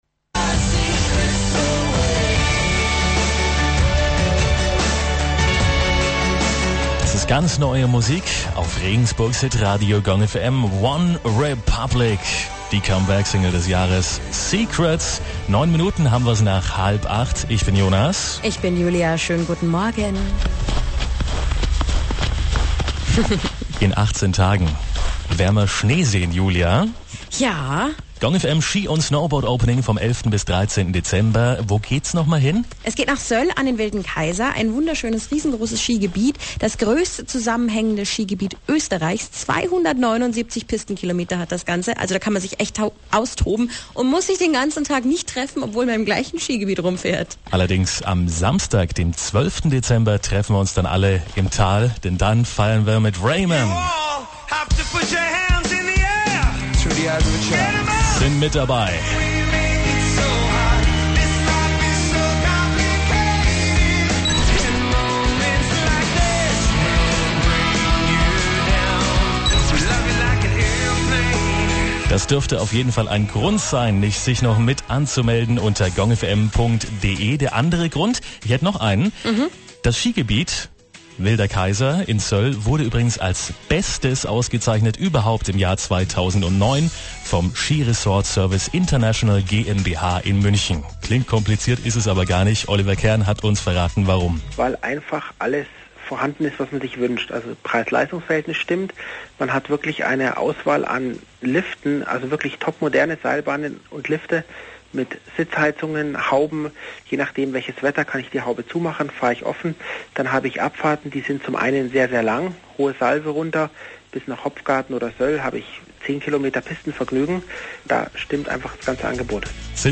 Radiointerviews Zum Abspielen bitte auf das jeweilige Interview klicken. Radio Tirol - Interview zur Verleihung der Top Skiresorts Radio Gong FM Regensburg - Interview über SkiWelt Wilder Kaiser Brixental Schweizer Radiostation - Interview über Skiresort